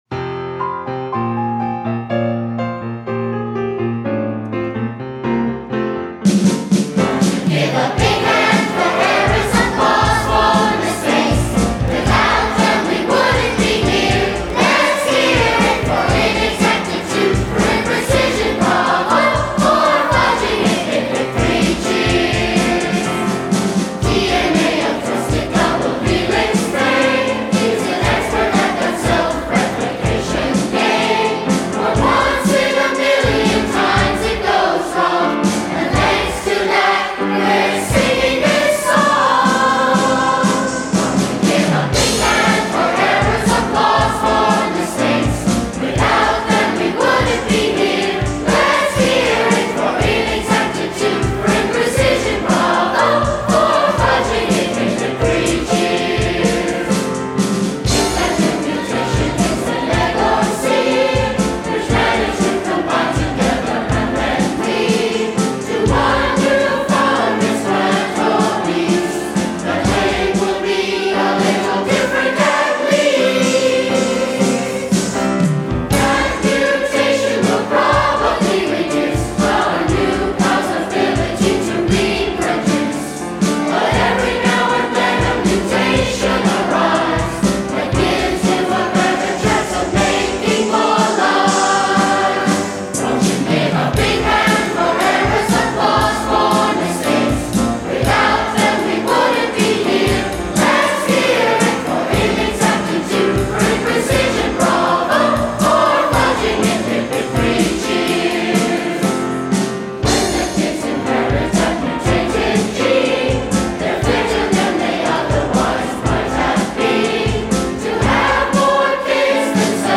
Below you can hear the 2007 Festival Chorus performing Lifetime: Songs of Life and Evolution.
Performed with members of the Haggerty School Chorus.